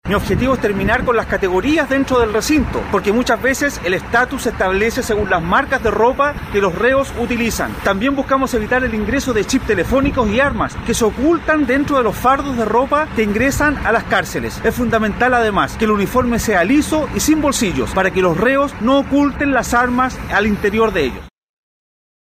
El congresista manifestó que el propósito es terminar con las categorizaciones al interior de los penales.